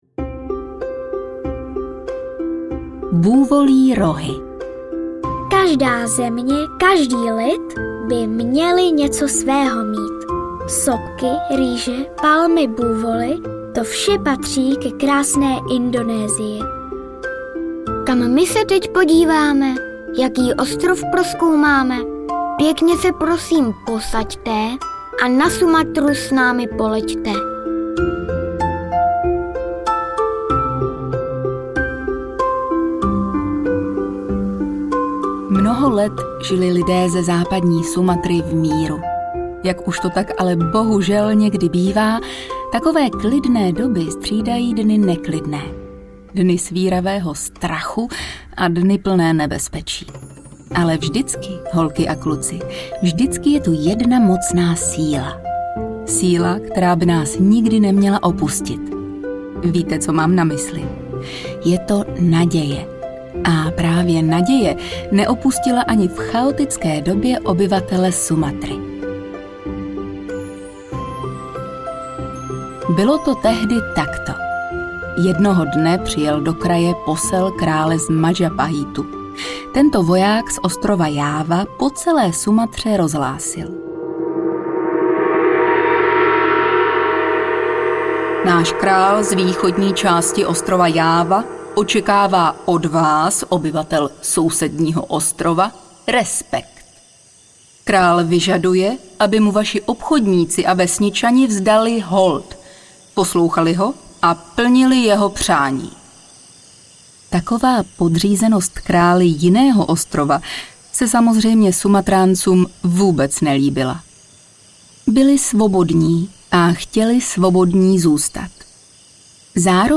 Ukázka z knihy
Vydejte se s vašimi dětmi na CESTU DO DALEKÉ ZEMĚ – a zajistěte přitom tamním školákům a jejich rodinám zdravotní péči a vzdělání!! Jedinečná audiokniha plná pohádek, říkanek a písniček v indonéském duchu. 77 minut originální zábavy pro vaše dítě – ideální do auta nebo do ouška před usnutím!